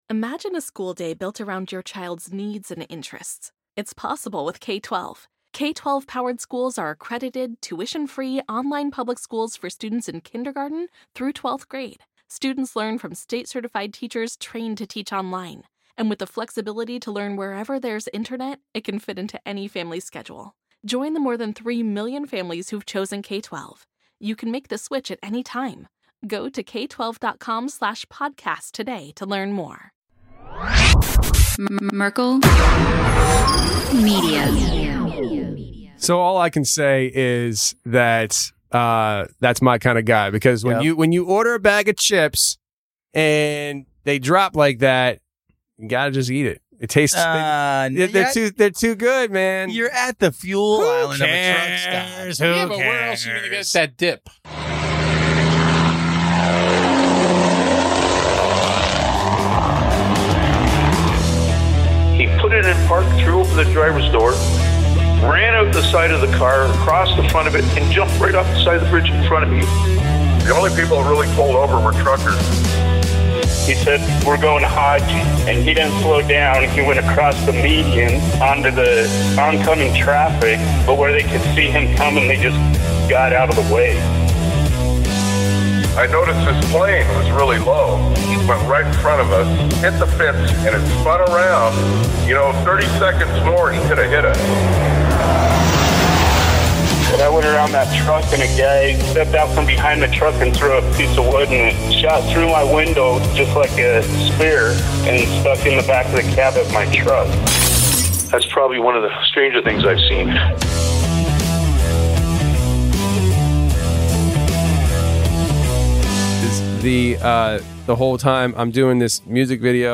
We discuss how 2021 unfolded for all of us, what we see coming on the horizon, and where the trucking industry is possibly headed. We also play a long line of voicemails, which have been piling up for a quite while!